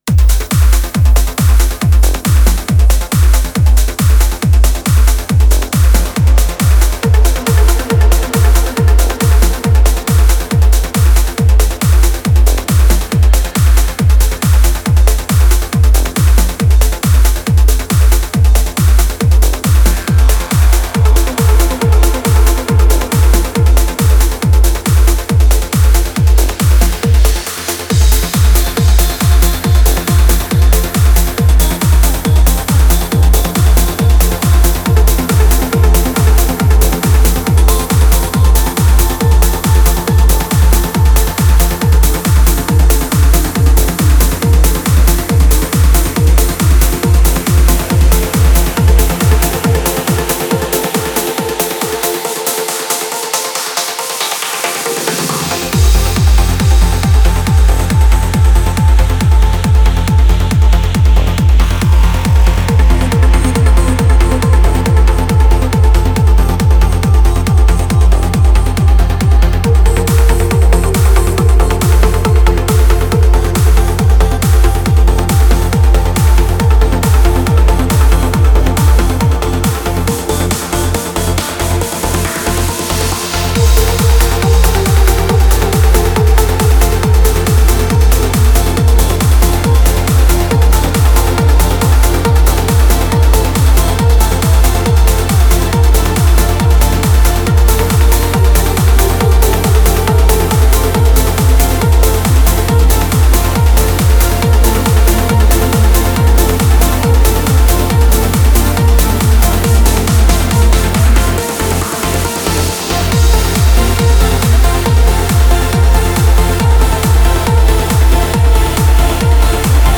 Стиль: Vocal Trance